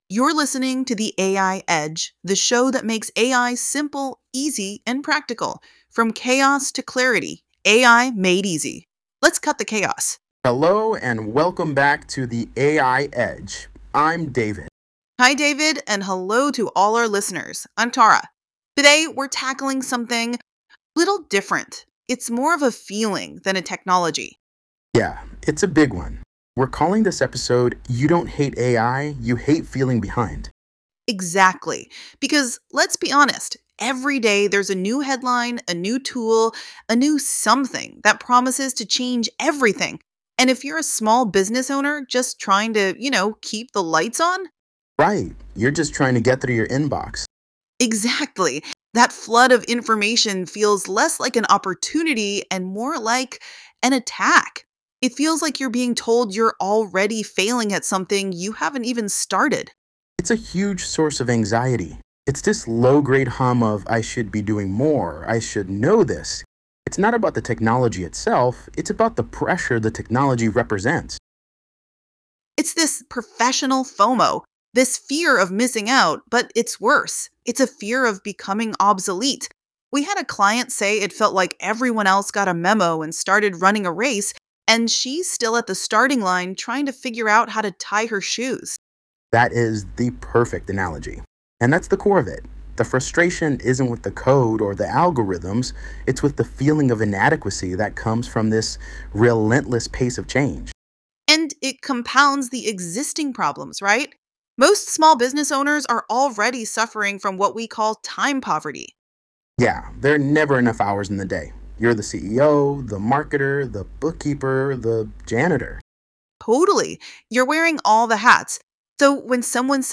In this episode of The AI Edge, we’re having a real conversation about AI, the fear around it, the misinformation people are spreading, and why businesses that ignore AI are putting themselves at a serious disadvantage.